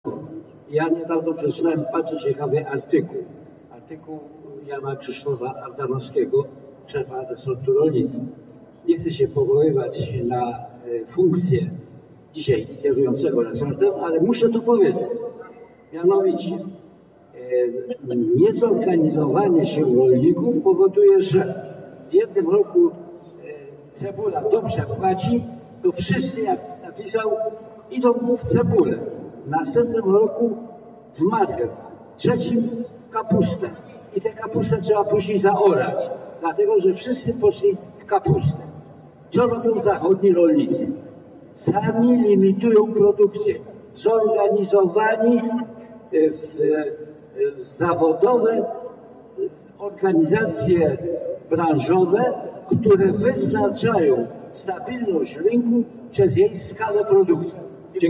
Debata ministrów podczas V Forum Rolniczego w Bydgoszczy
wypowiedz_zieba.mp3